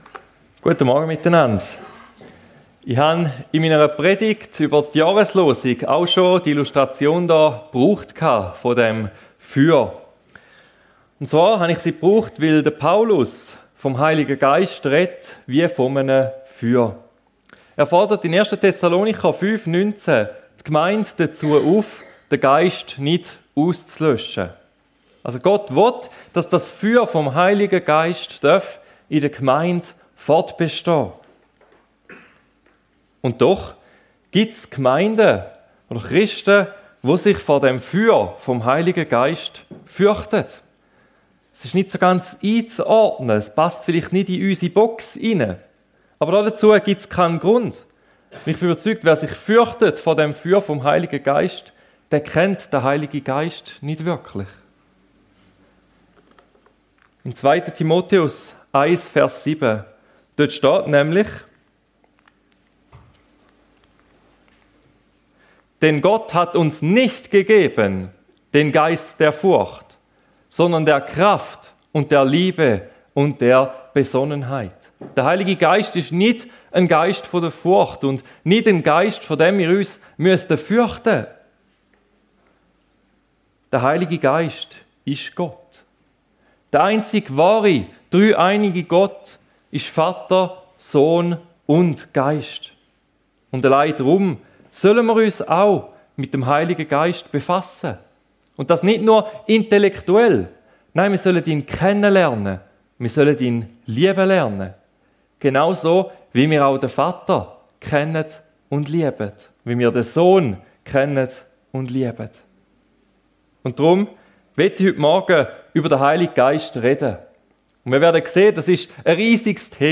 Der Heilige Geist ~ FEG Sumiswald - Predigten Podcast